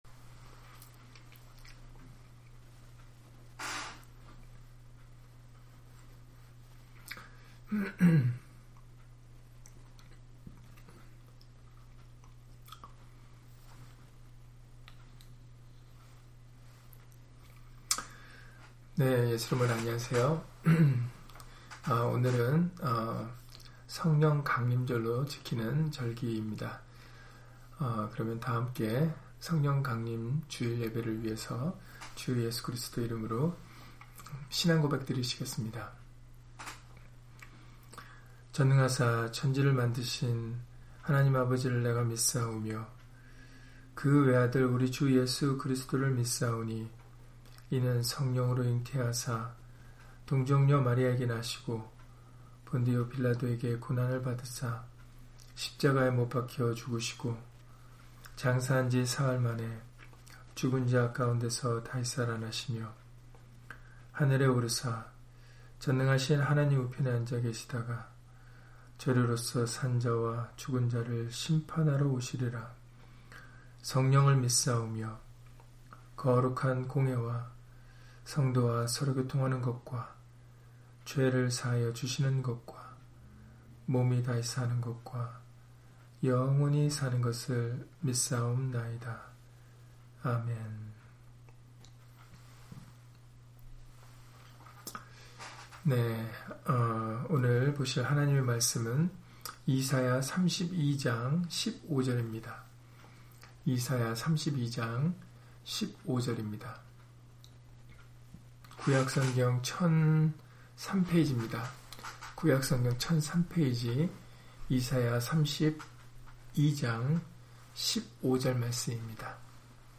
이사야 32장 15절 [성령강림 주일] - 주일/수요예배 설교 - 주 예수 그리스도 이름 예배당